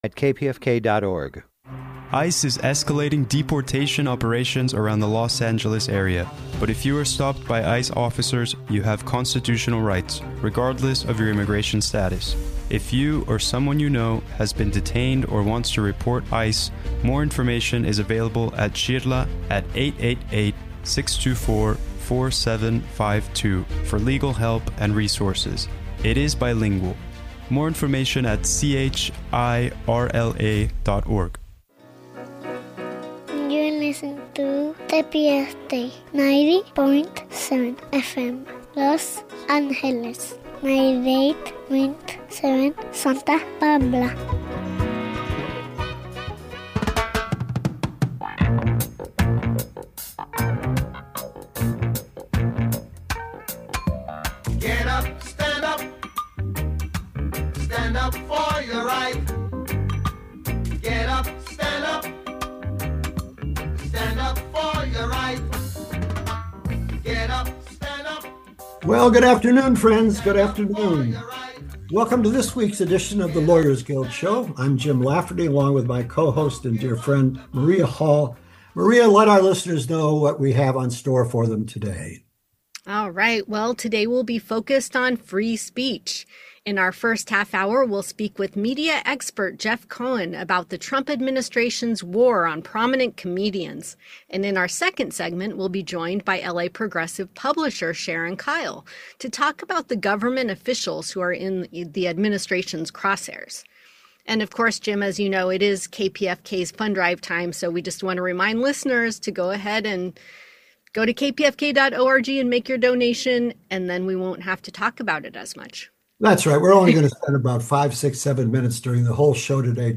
A public affairs program where political activists and experts discuss current political developments and progressive movements for social change.